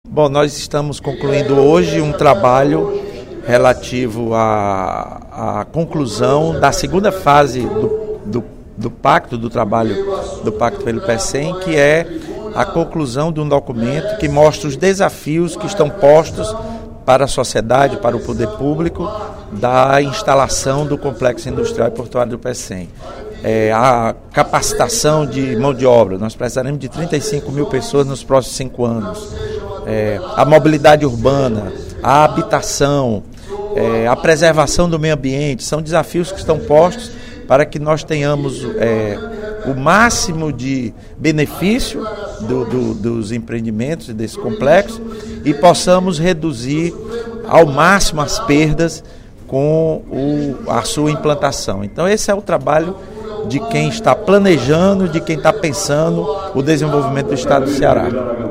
O deputado Lula Morais (PCdoB) anunciou, durante o primeiro expediente da sessão plenária desta sexta-feira (22/03), a finalização de mais uma fase do Pacto pelo Pecém.